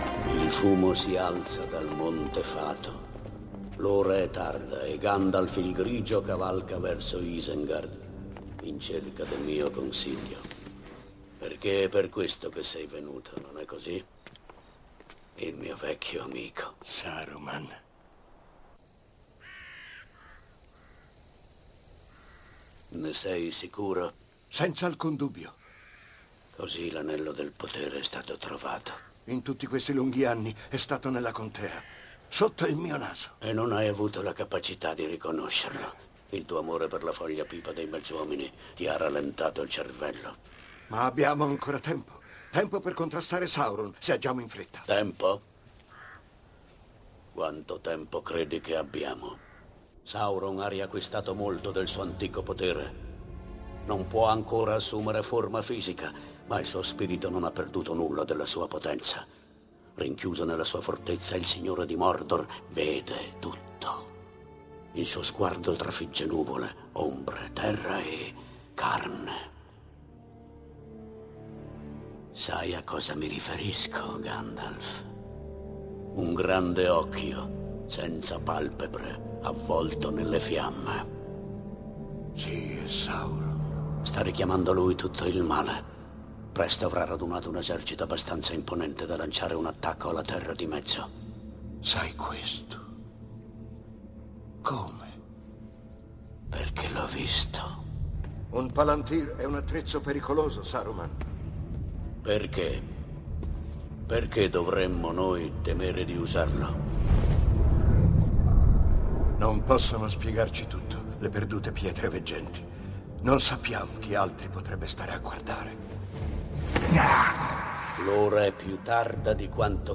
voce di Omero Antonutti nel film "Il Signore degli Anelli: La Compagnia dell'Anello", in cui doppia Christopher Lee.